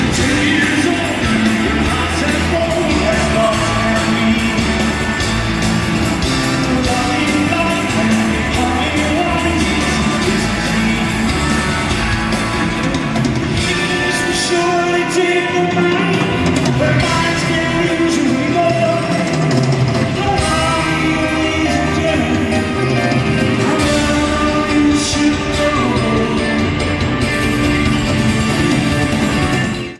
Comments: Poor audience recording.
Sound Samples (Compression Added):